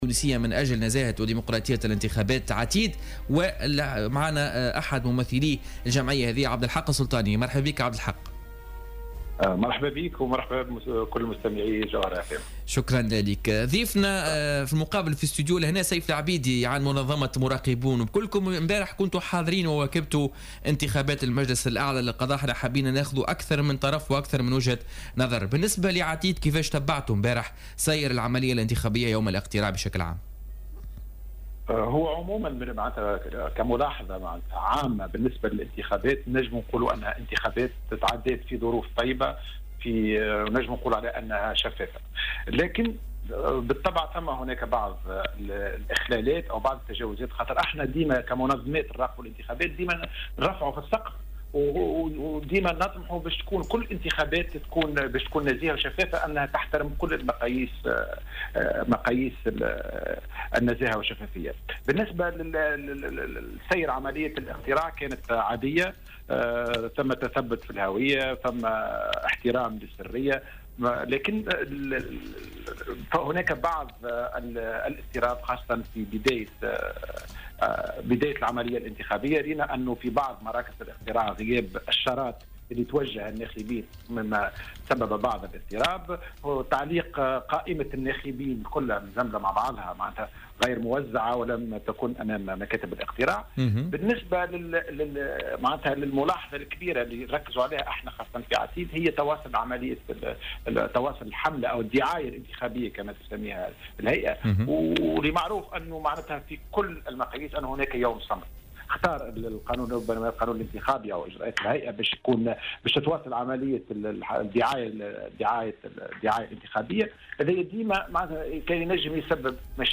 وأضاف في اتصال هاتفي مع "بوليتيكا" انه لوحظ بعض الاضطراب في بداية العملية الانتخابية على غرار غياب الشارات التي توجه الناخبين كما تم تعليق قائمة الناخبين بطريقة غير موزعة.